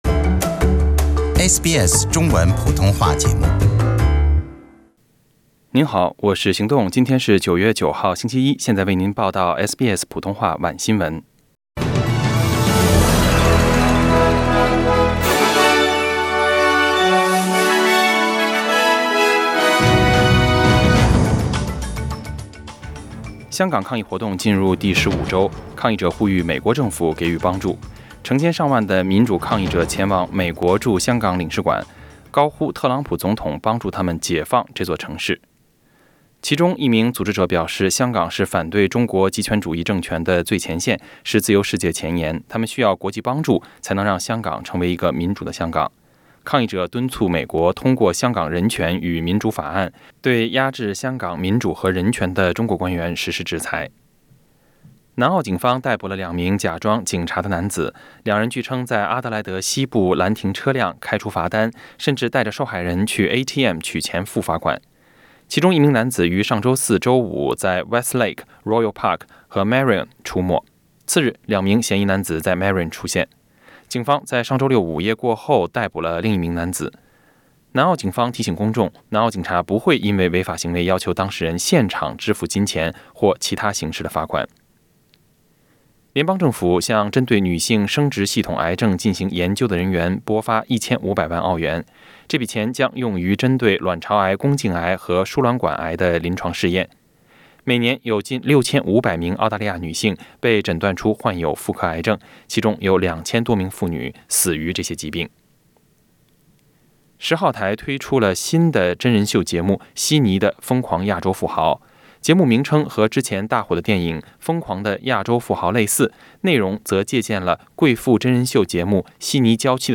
SBS晚新闻 （9月9日）